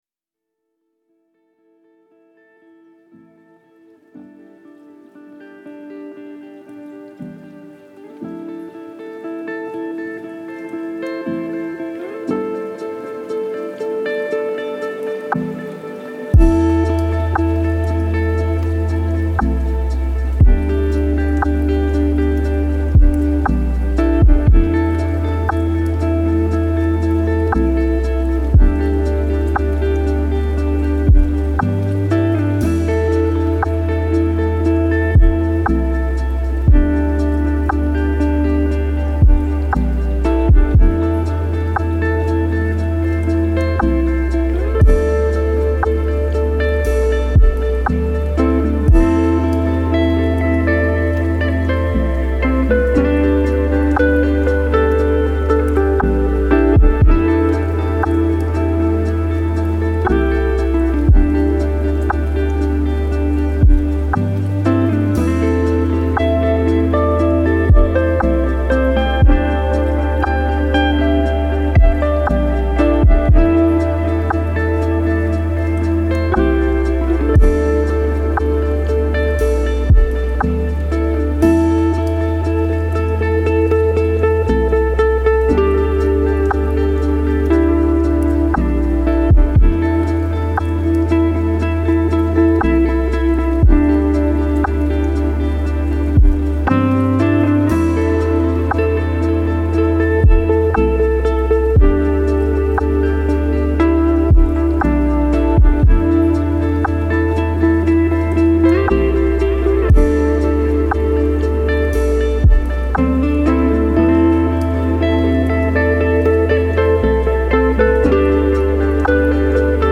Aucun bruit parasite, aucune coupure soudaine.